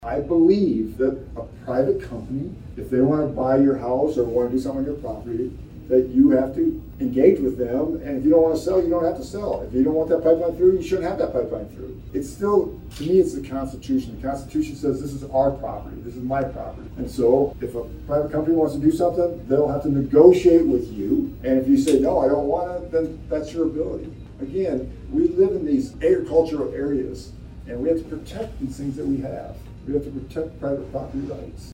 A group gathered inside one of the back dining areas at Pizza Ranch while Feenstra gave his speech on his campaign run.